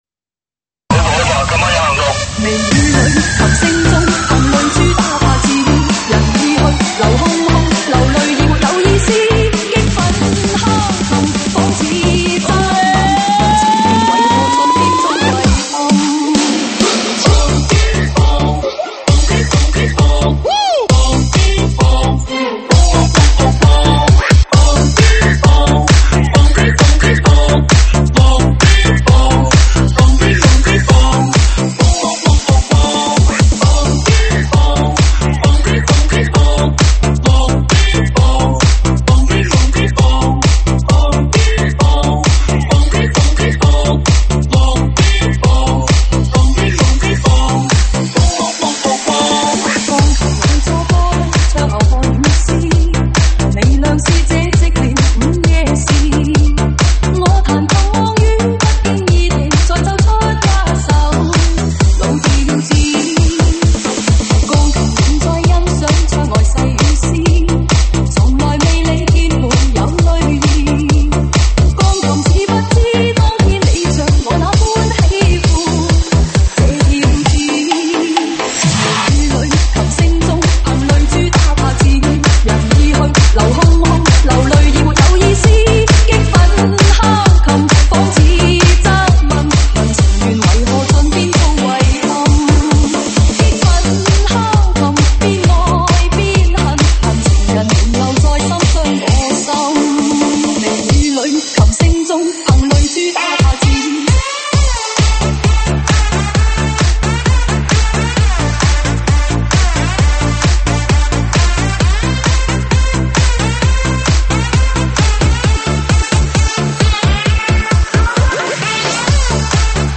收录于(现场串烧)提供在线试听及mp3下载。